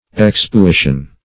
Search Result for " exspuition" : The Collaborative International Dictionary of English v.0.48: Exspuition \Ex`spu*i"tion\, n. [L. exspuitio; ex out + spuere to spit: cf. F. exspuition.]